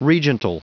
Prononciation du mot regental en anglais (fichier audio)
Prononciation du mot : regental